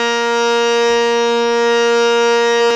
52-key11-harm-a#3.wav